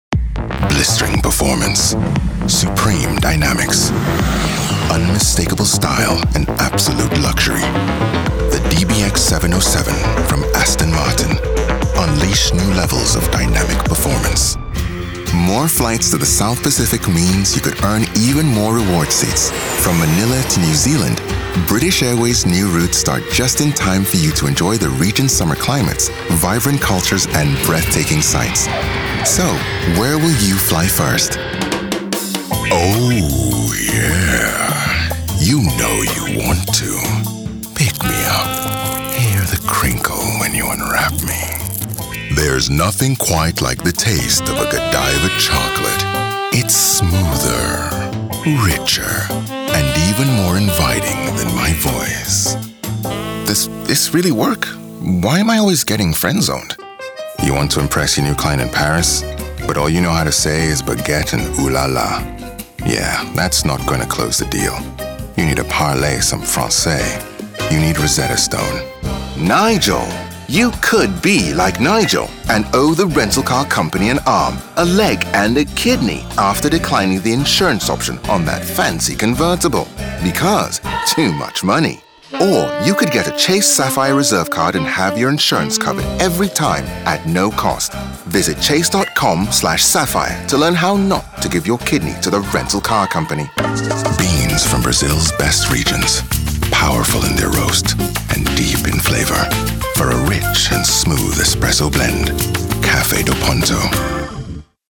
Tief, Natürlich, Unverwechselbar, Verspielt, Vielseitig
Kommerziell